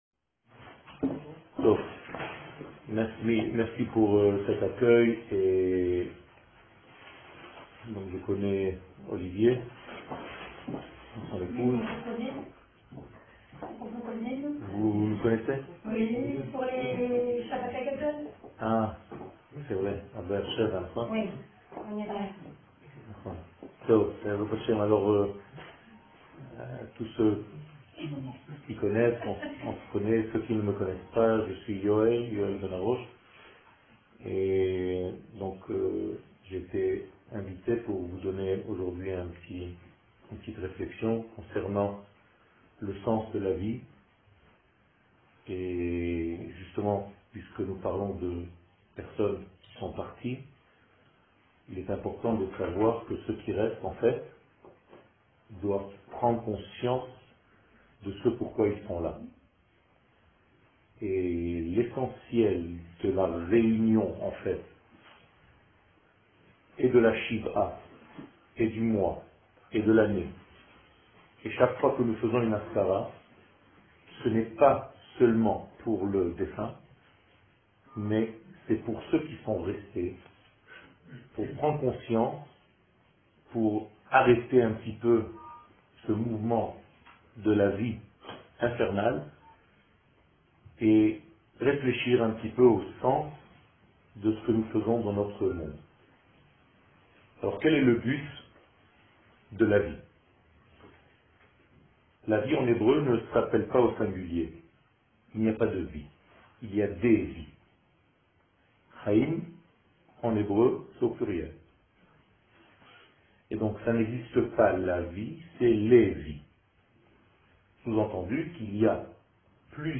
Le sens de la vie Hassidouth שיעור מ 25 נובמבר 2016 01H 10MIN הורדה בקובץ אודיו MP3 (12.18 Mo) הורדה בקובץ אודיו M4A (8.44 Mo) TAGS : Etude sur la Gueoula Moussar Torah et identite d'Israel שיעורים קצרים